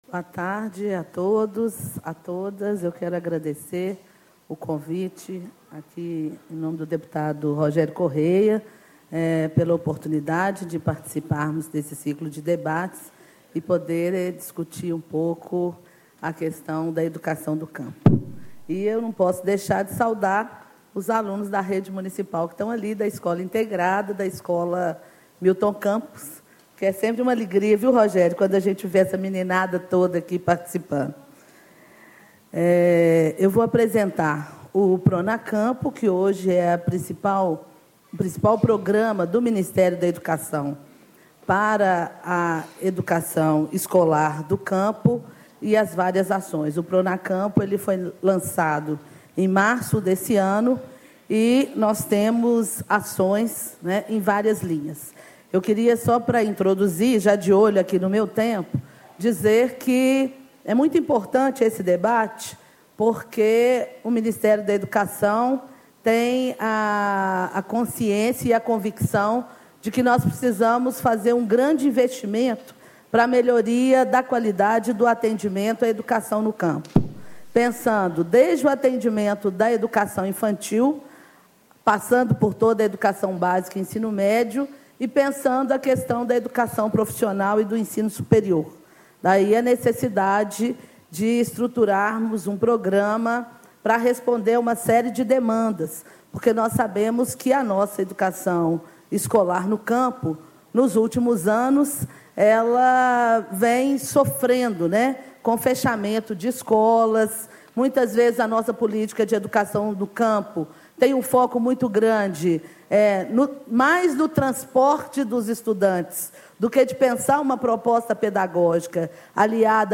Macaé Evaristo, Diretora de Políticas para Educação do Campo, Indígena e para as Relações Étnico-Raciais do Ministério da Educação. Painel: Educação do Campo e Juventude
Discursos e Palestras